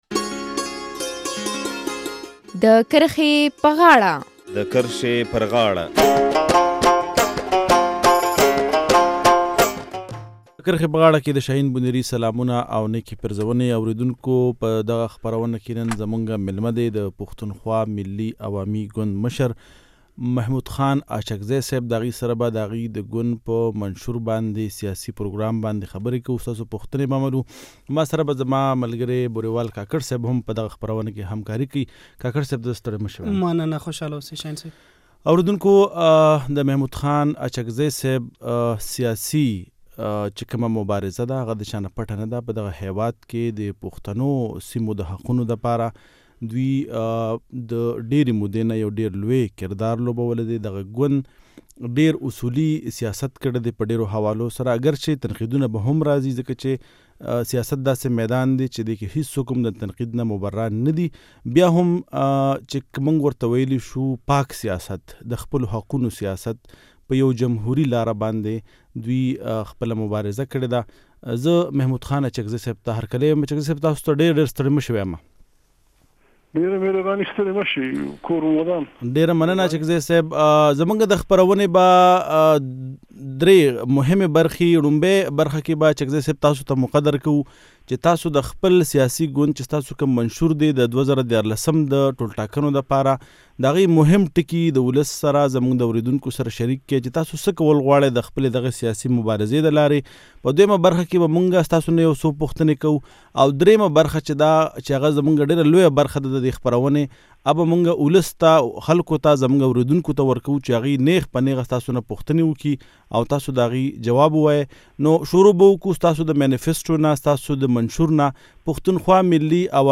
د پښتونخوا ملي عوامي ګوند له مشر محمود خان اڅکزي سره مرکه